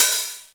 909OHH.wav